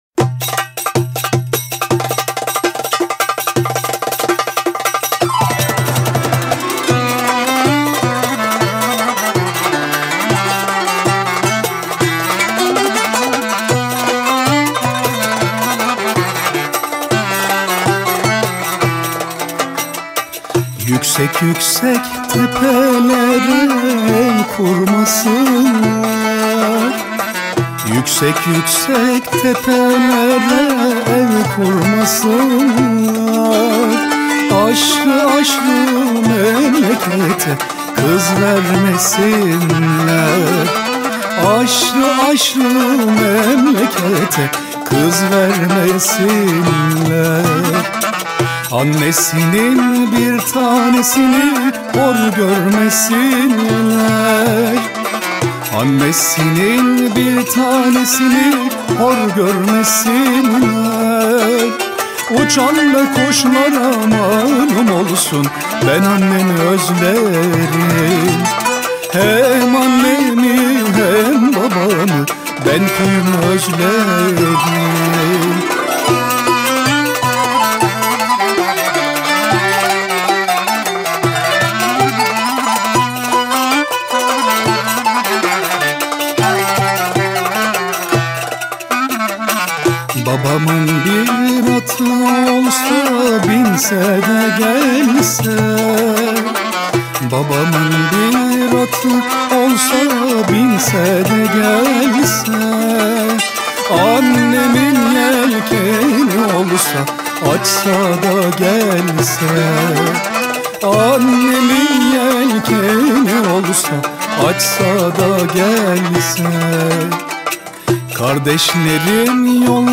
Trakya Bölgesi: Yüksek Yüksek Tepelere
Yöresel türkü.